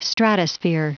Prononciation du mot stratosphere en anglais (fichier audio)
Prononciation du mot : stratosphere